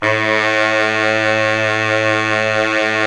Index of /90_sSampleCDs/Roland LCDP07 Super Sax/SAX_Sax Ensemble/SAX_Sax Sect Ens
SAX 2 BARI0K.wav